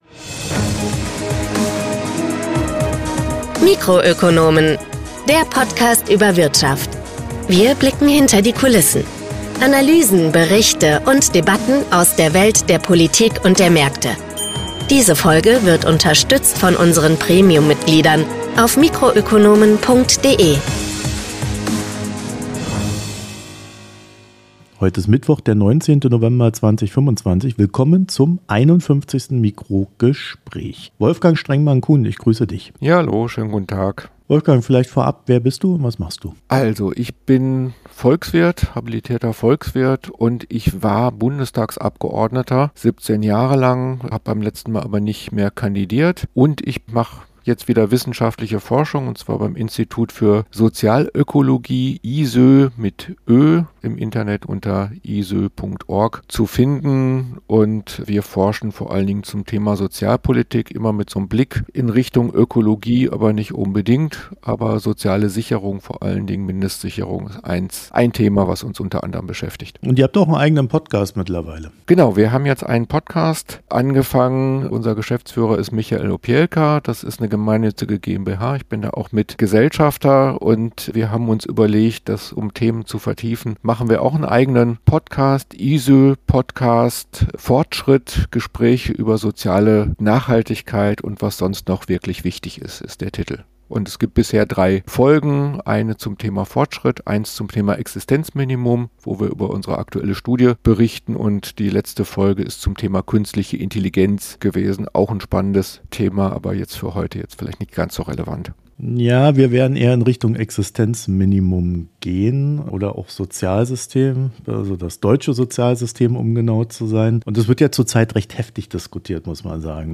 In diesem Gespräch